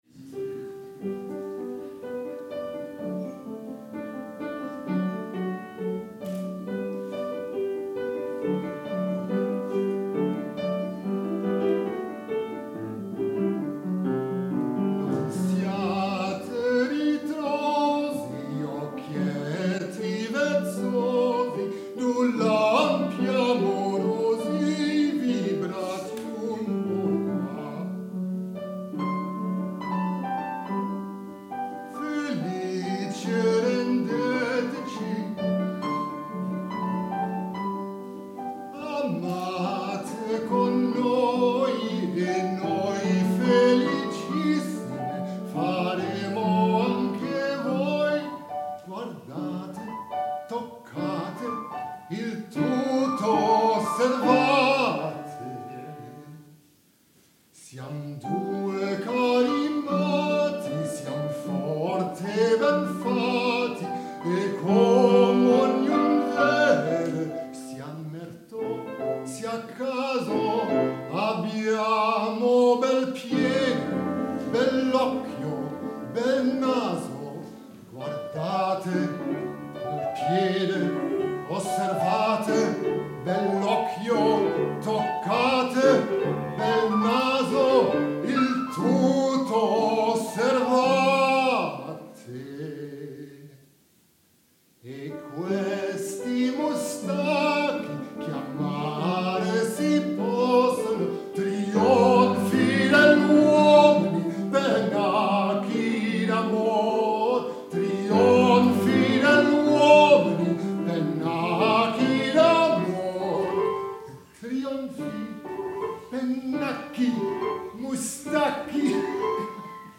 Hier kun je mijn zang beluisteren:
Non siate ritrosi (Guglielmo) - W.A. Mozart (Nr. 15 Aria uit: Cosi fan tutte)